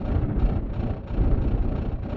Index of /musicradar/rhythmic-inspiration-samples/110bpm